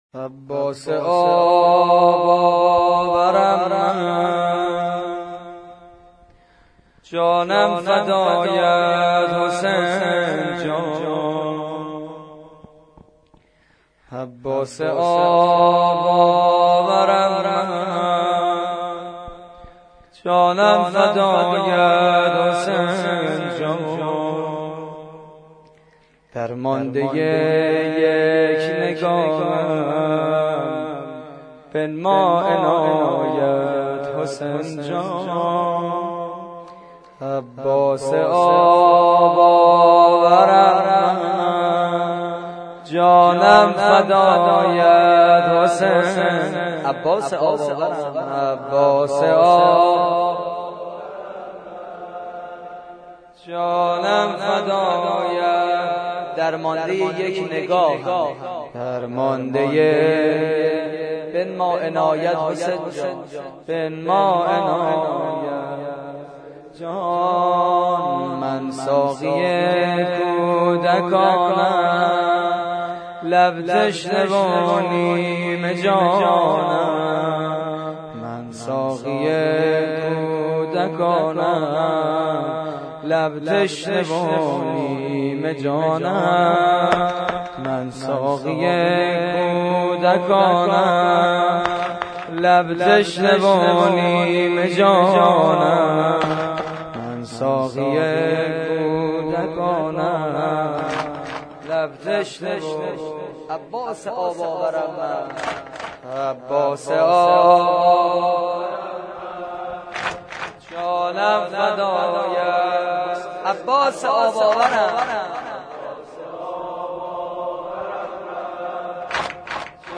واحد: عباس آب آورم من